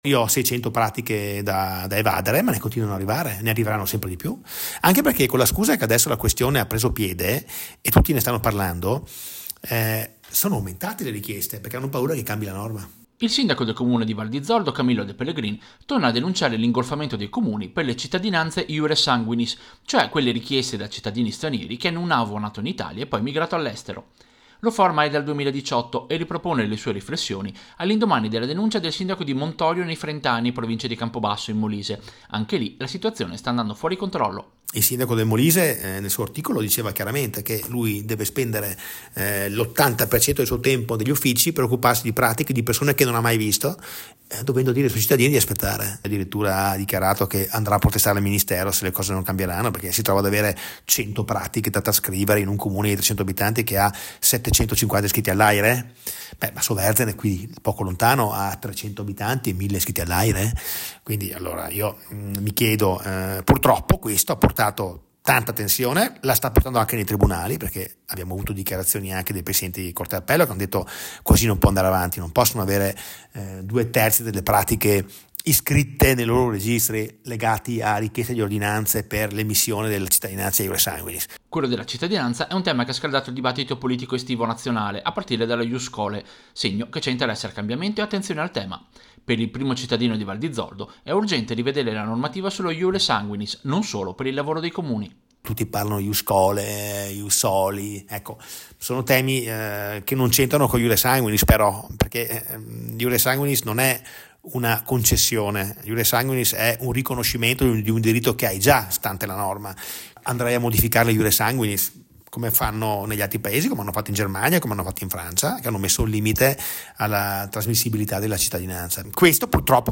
Servizio-Cittadinanze-AIRE-Val-di-Zoldo.mp3